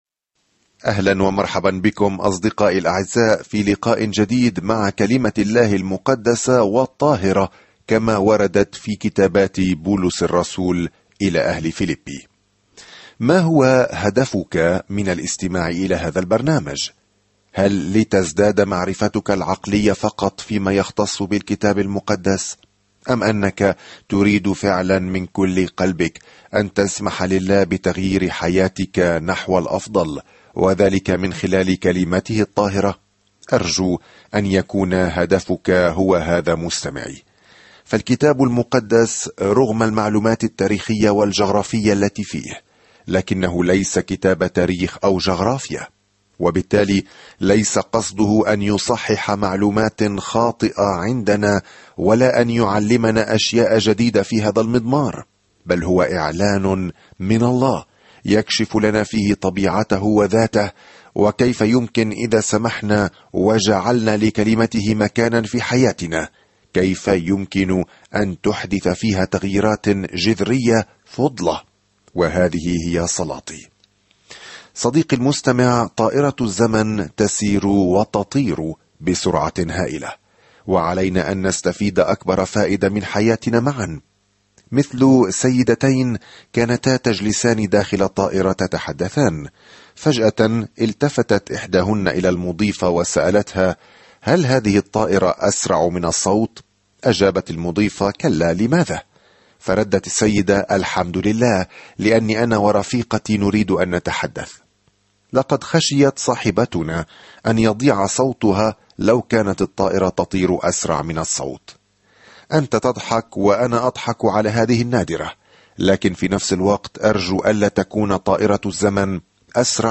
الكلمة فِيلِبِّي 8:2-11 يوم 7 ابدأ هذه الخطة يوم 9 عن هذه الخطة إن رسالة "الشكر" هذه الموجهة إلى أهل فيلبي تمنحهم منظورًا بهيجًا للأوقات الصعبة التي يعيشونها وتشجعهم على اجتيازها بتواضع معًا. سافر يوميًا عبر رسالة فيلبي وأنت تستمع إلى الدراسة الصوتية وتقرأ آيات مختارة من كلمة الله.